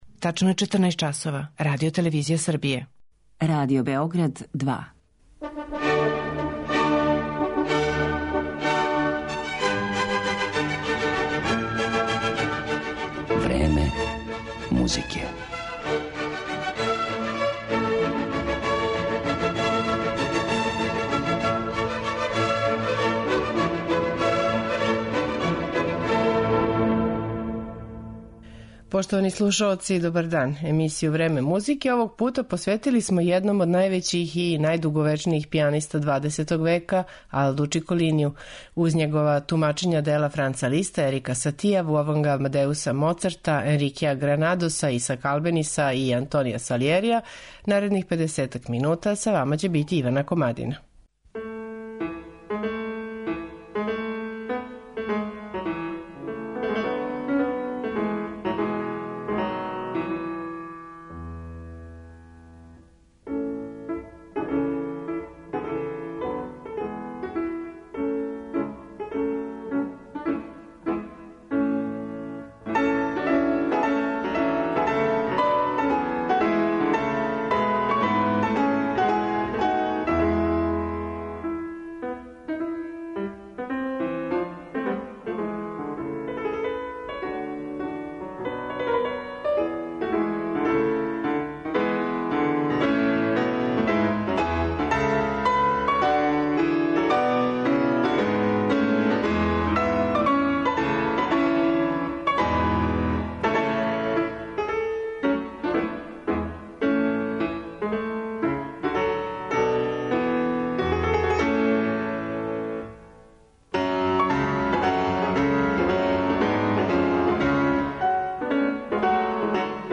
У данашњем Времену музике , које смо посветили једном Алду Чиколинију, слушаћете његова тумачења дела Франца Листа, Ерика Сатија, Волфганга Амадеуса Моцарта, Енрикеа Гранадоса, Исака Албениса и Антонија Салијерија.